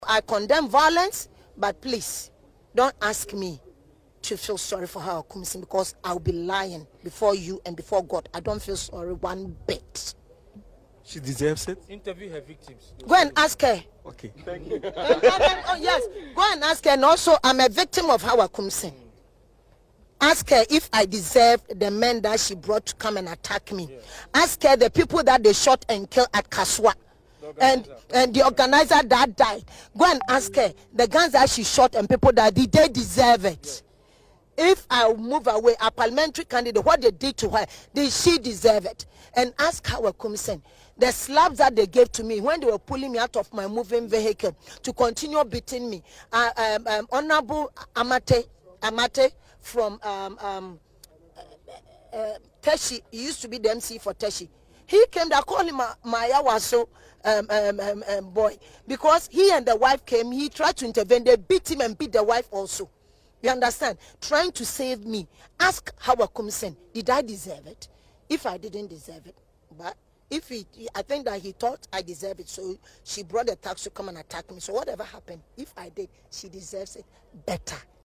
In an interview with Accra-based Citi News, Dr. Bissiw condemned the violence that disrupted the election at 19 polling stations but made it clear she cannot empathize with the former Fisheries Minister.